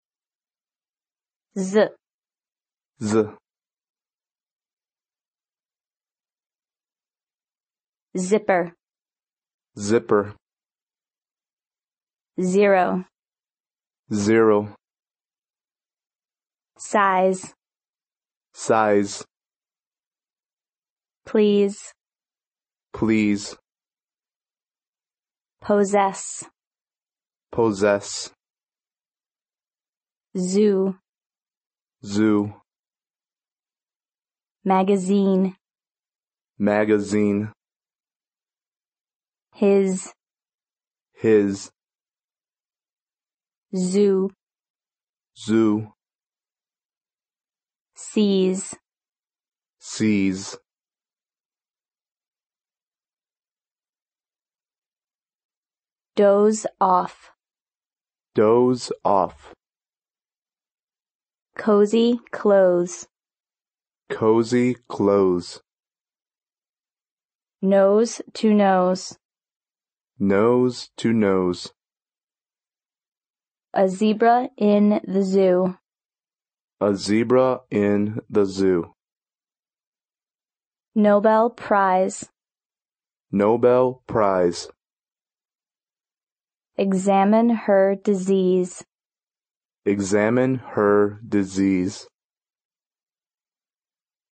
英语国际音标：摩擦音[z] 听力文件下载—在线英语听力室